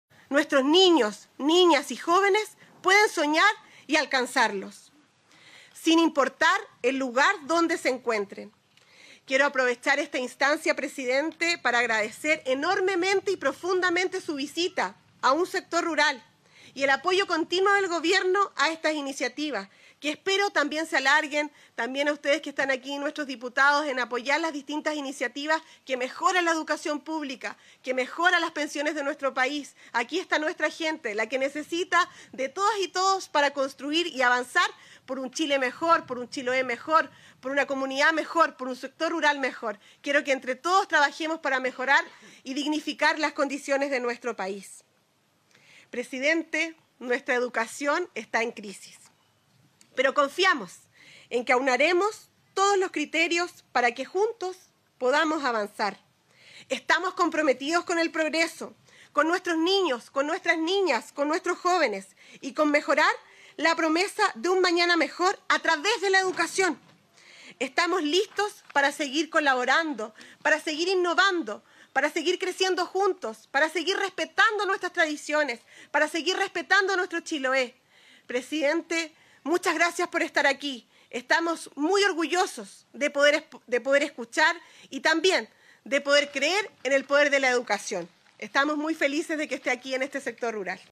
A su vez, la alcaldesa Javiera Yáñez mostró su satisfacción por haber gestionado este importante adelanto que otorgará mejores condiciones a los estudiantes y su comunidad educacional: